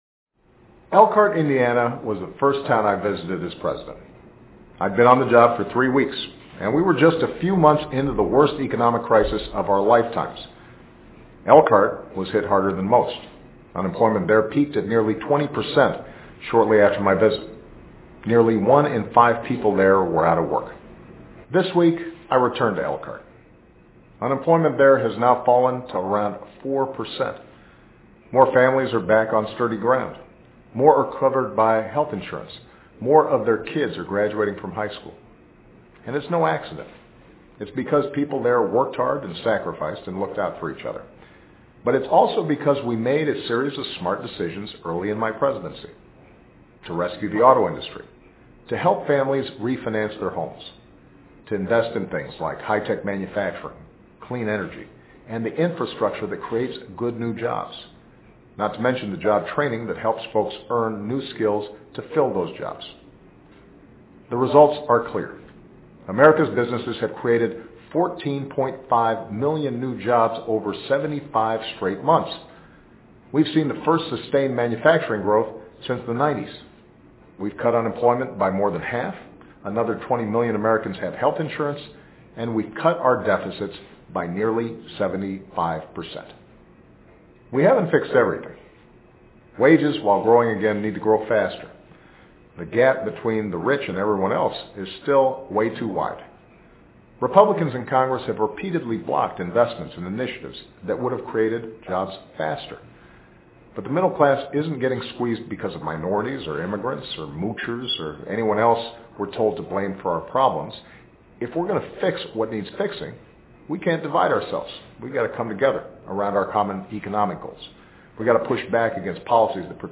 奥巴马每周电视讲话：美国经济正在复苏 听力文件下载—在线英语听力室